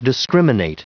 Prononciation du mot discriminate en anglais (fichier audio)